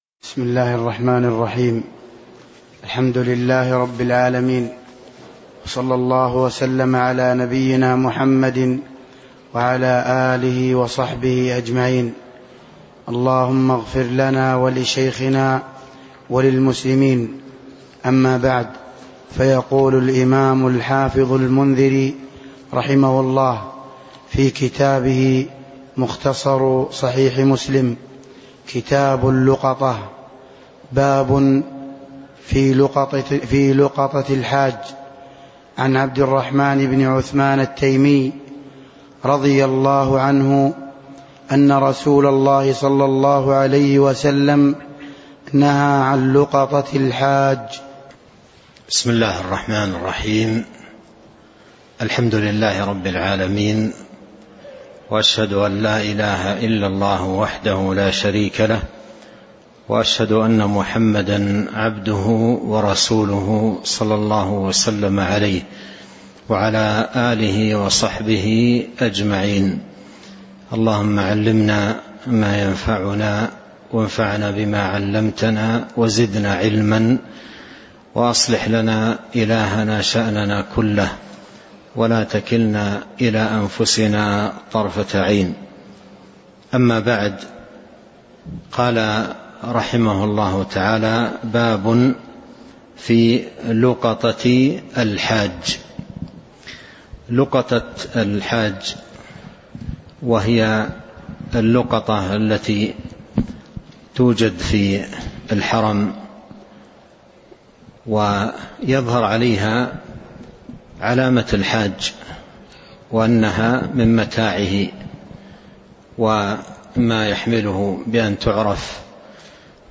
تاريخ النشر ١٩ ربيع الأول ١٤٤٣ هـ المكان: المسجد النبوي الشيخ: فضيلة الشيخ عبد الرزاق بن عبد المحسن البدر فضيلة الشيخ عبد الرزاق بن عبد المحسن البدر باب في لقطة الحاج (02)